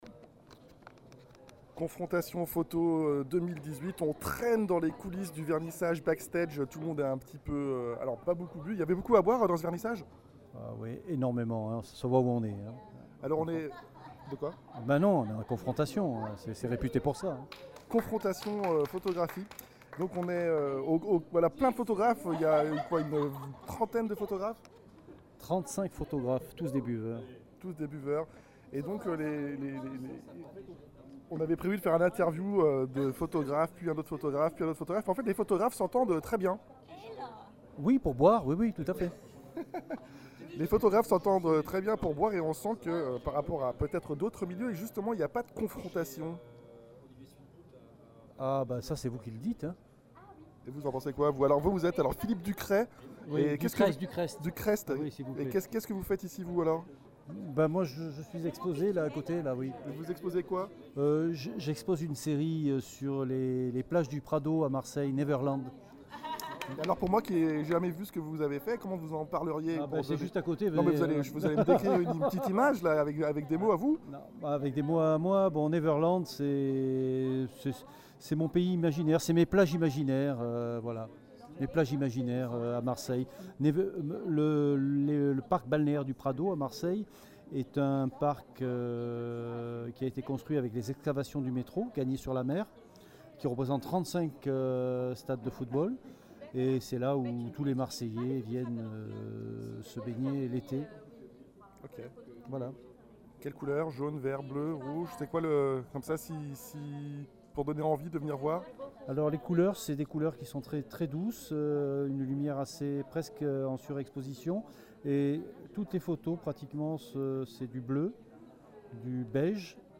Interview Ecole des Gobelins